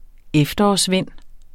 Udtale [ ˈεfdʌɒs- ]